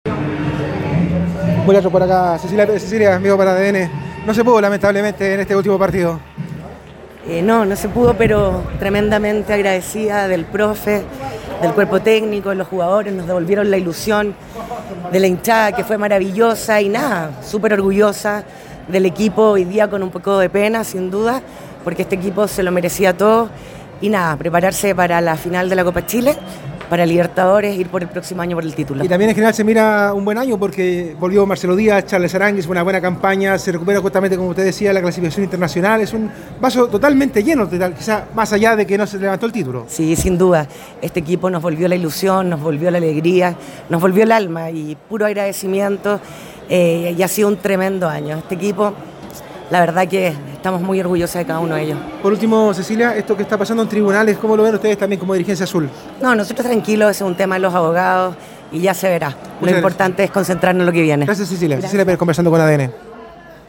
En diálogo con ADN Deportes, la vicepresidenta de Azul Azul valoró la campaña del elenco dirigido por Gustavo Álvarez a pesar de no haber podido levantar el título del Campeonato Nacional.
Tras el encuentro en el Estadio Nacional, Cecilia Pérez, vicepresidenta de Azul Azul, habló con ADN Deportes y lamentó no haber podido bajar la estrella 19, aunque destacó lo que fue campaña del equipo de Gustavo Álvarez.